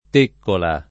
teccola [ t % kkola ]